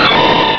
pokeemerald / sound / direct_sound_samples / cries / breloom.aif